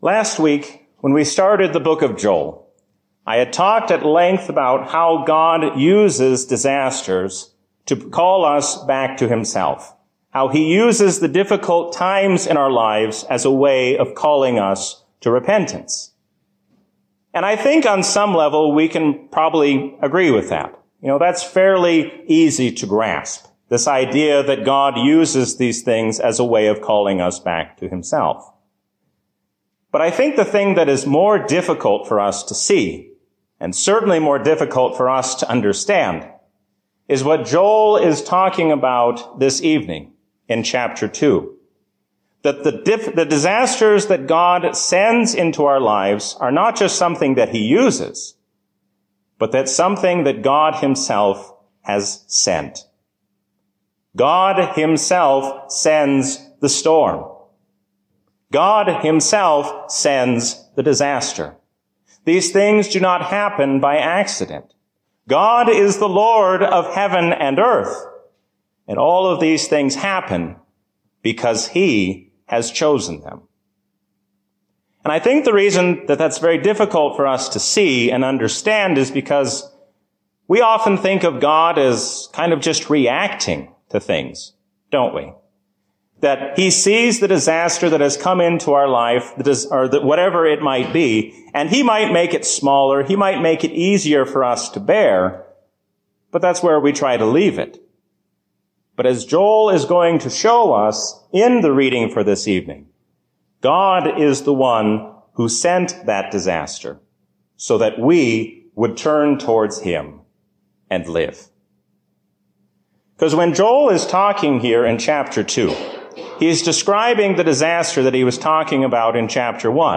A sermon from the season "Lent 2020." Pursue Jesus in faith and never let go.